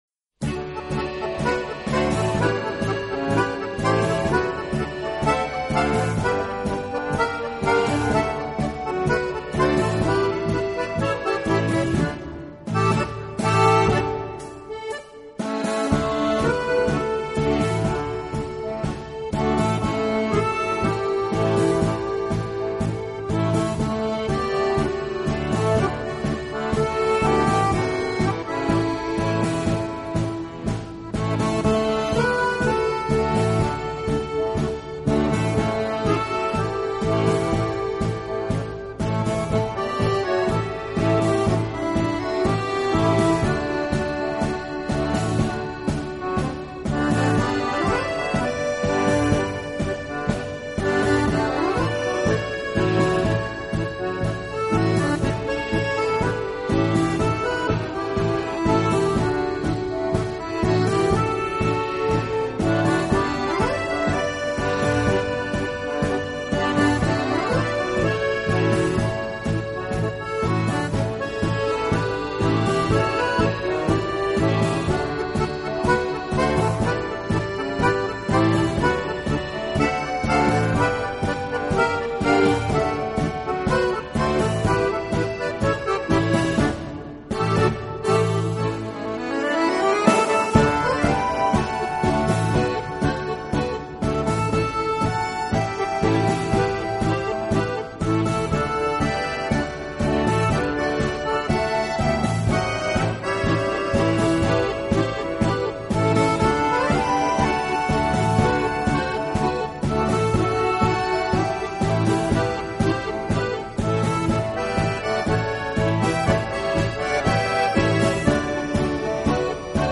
轻音乐 唱片公司